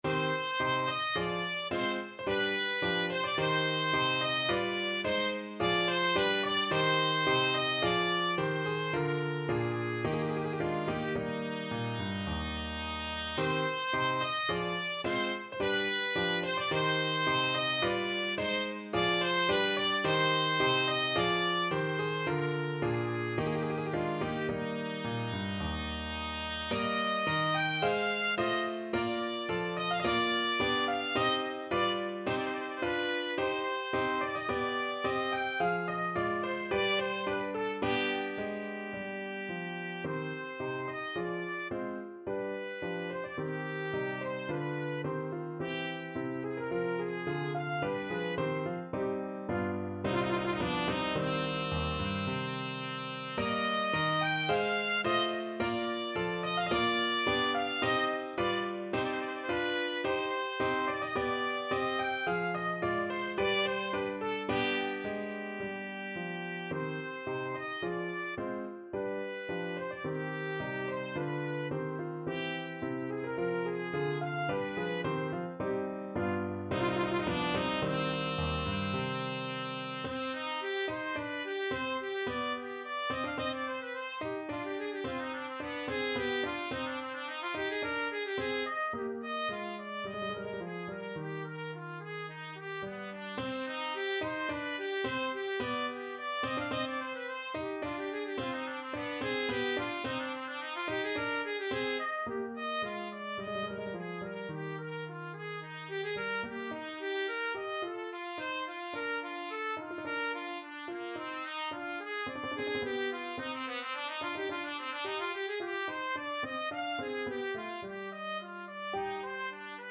~ = 54 Moderato
3/4 (View more 3/4 Music)
Classical (View more Classical Trumpet Music)